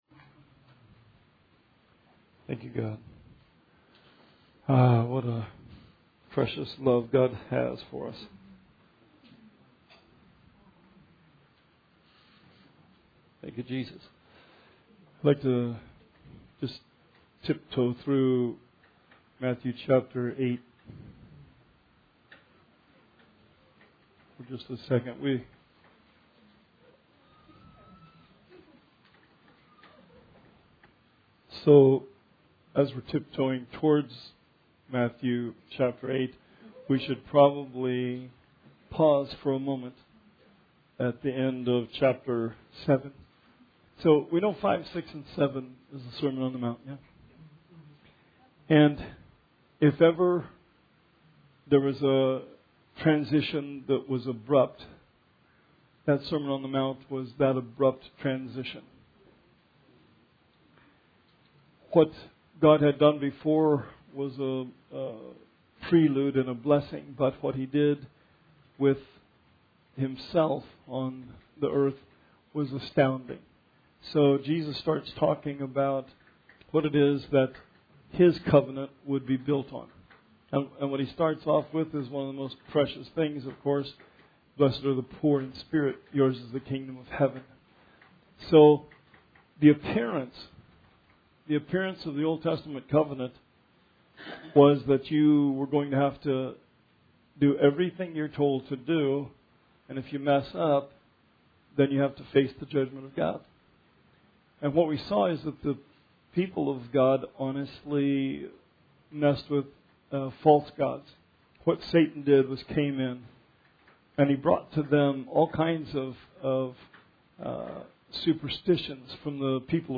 Bible Study 10/2/19